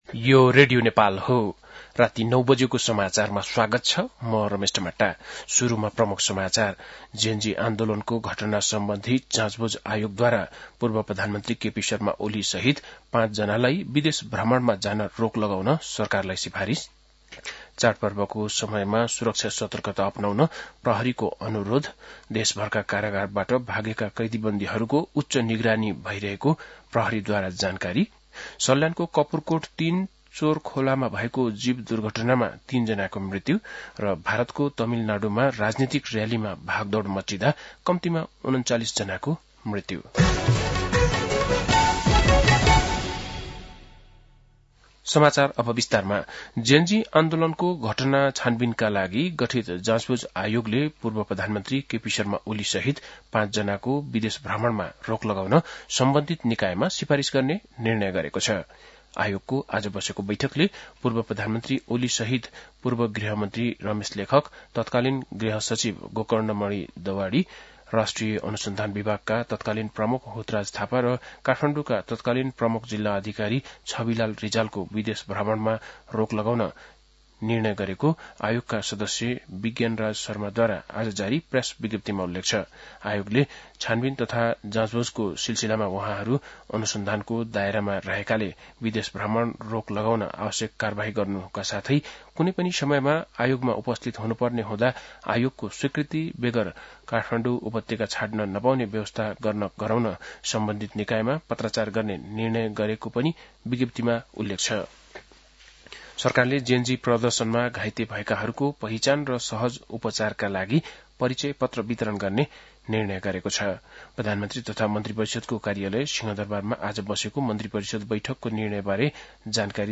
बेलुकी ९ बजेको नेपाली समाचार : १२ असोज , २०८२
9-pm-nepali-news-6-12.mp3